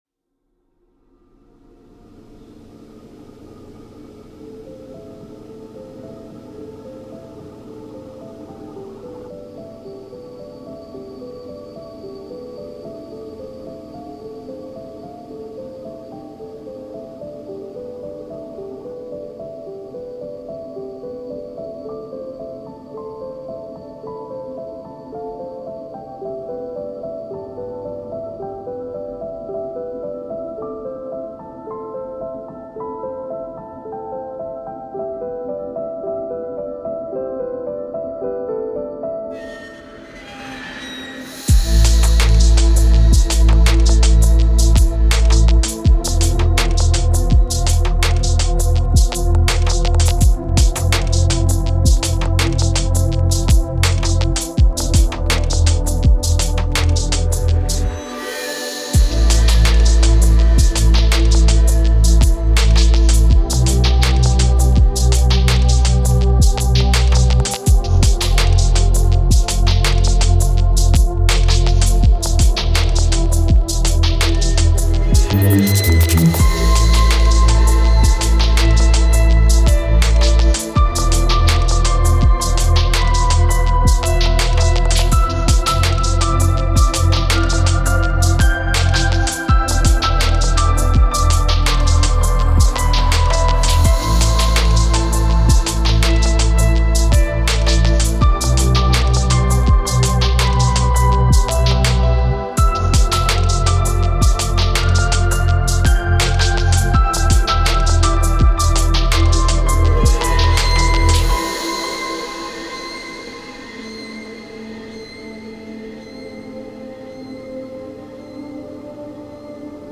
Стиль: Chillout / Lounge / Ambient / Downtempo / Electronica